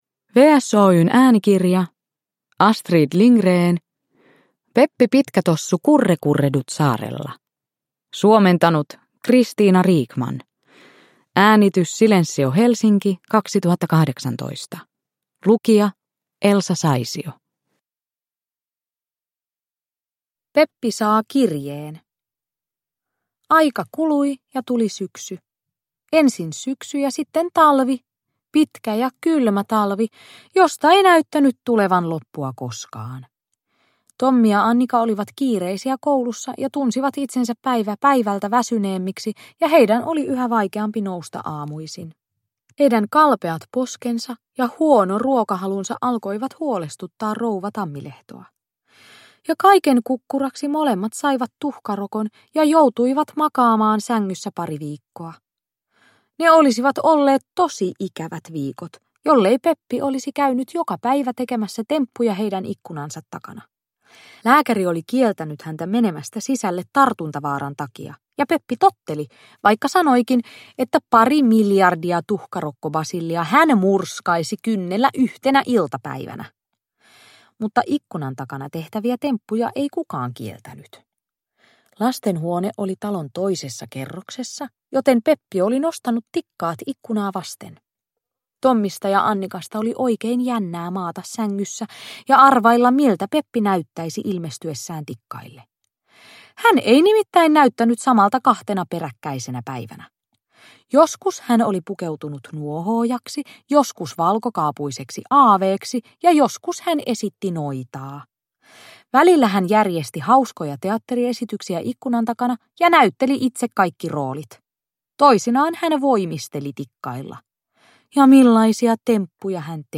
Peppi Pitkätossu Kurrekurreduttsaarella – Ljudbok – Laddas ner